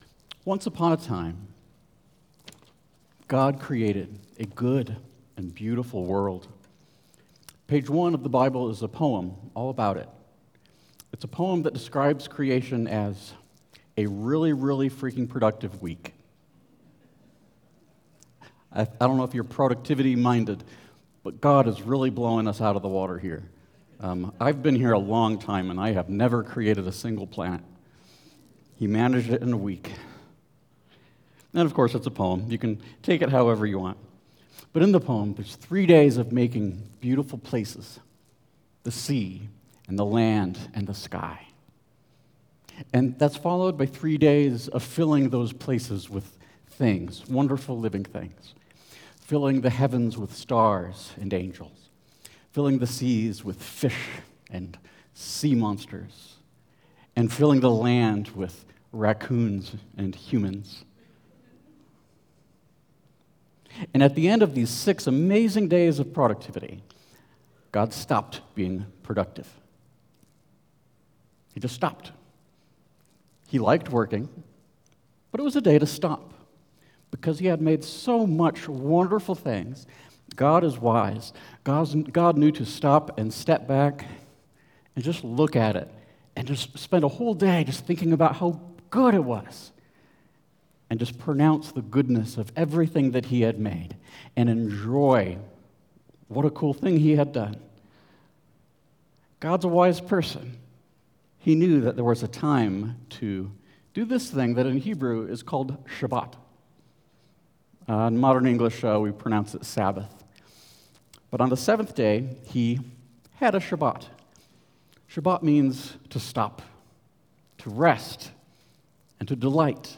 God designed us for a 7-day cycle of work and rest, but many people struggle to find time to relax. Listen to this sermon to discover the ancient spiritual practice of Sabbath.